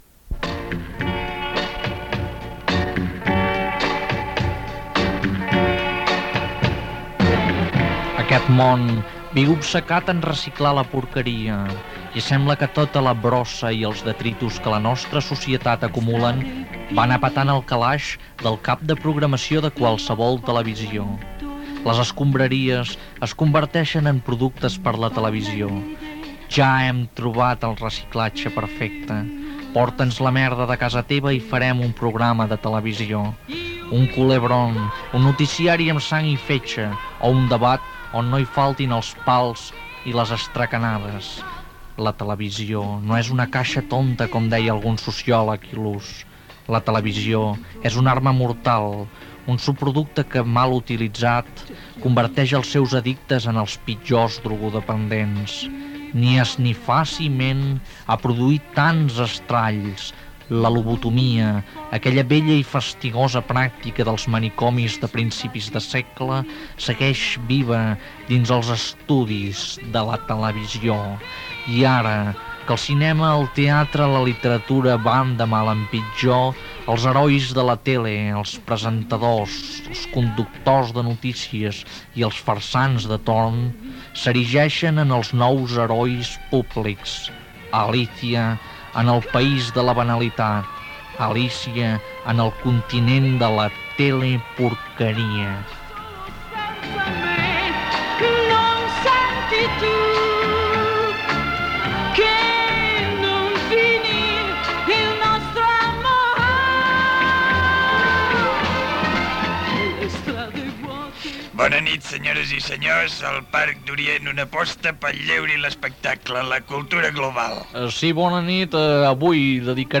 Comentari sobre la teleporqueria, presentació del programa dedicat a la televisió, tema musical, sàtira sobre la programació televisiva Gènere radiofònic Entreteniment